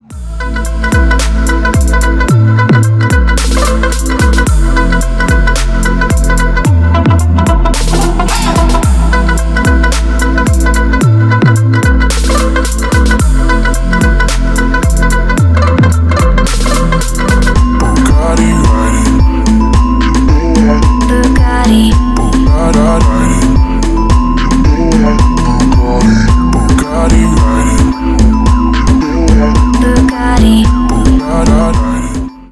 Поп Музыка # Танцевальные
клубные # громкие